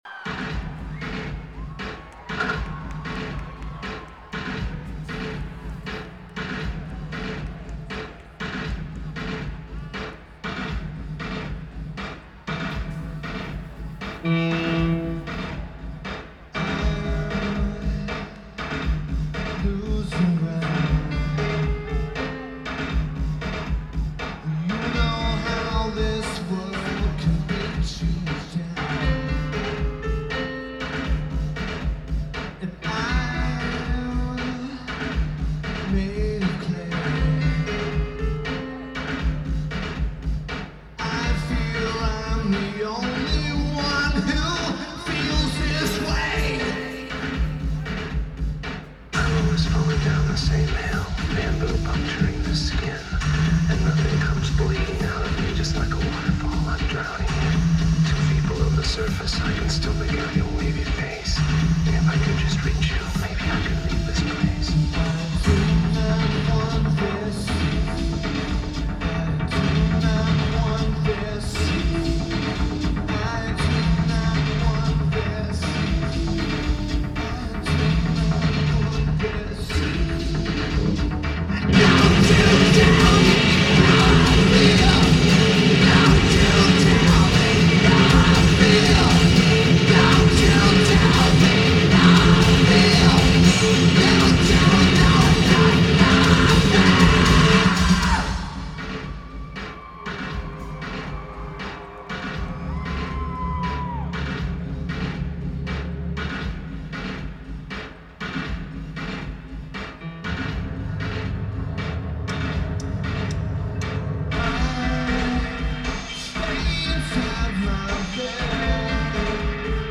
Milwaukee, WI United States
Lineage: Audio - AUD (Sonic Studios DSM6 + Sony TCD-D7)
Very good recording.